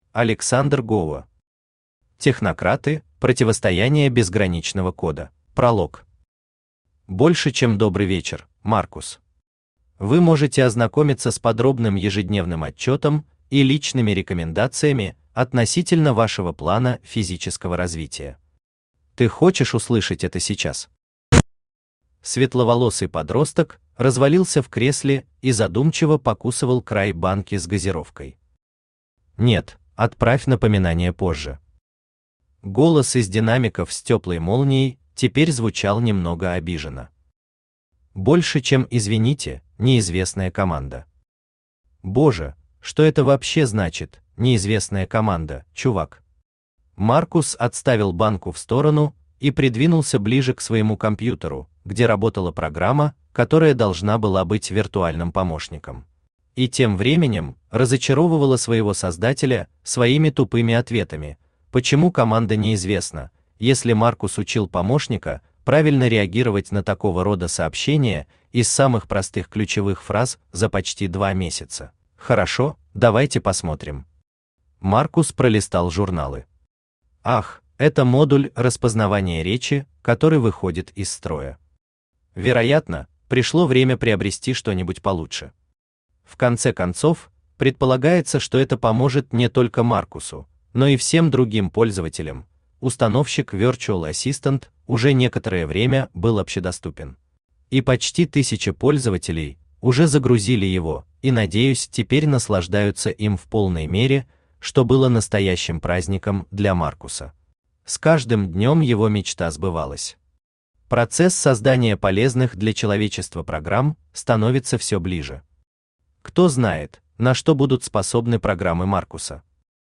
Aудиокнига Технократы: Противостояние Безграничного Кода Автор Александр Гоуа Читает аудиокнигу Авточтец ЛитРес.